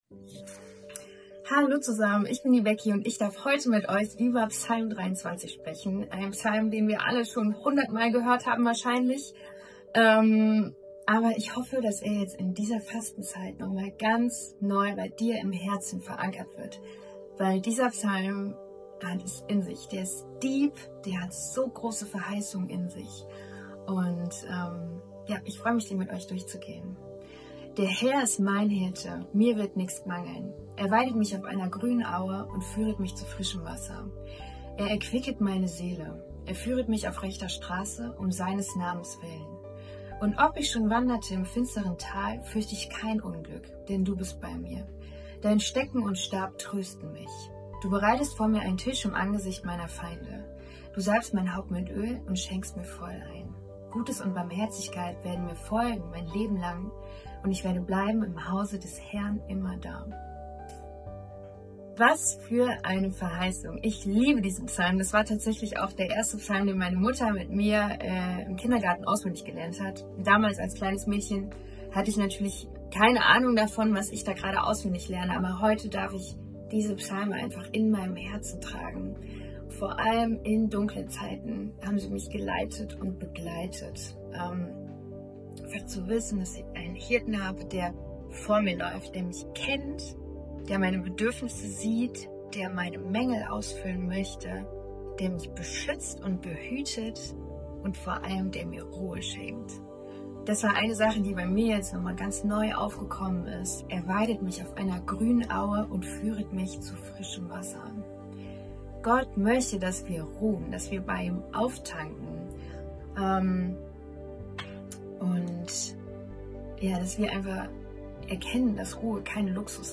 Tag 10 der Andacht zu unseren 21 Tagen Fasten & Gebet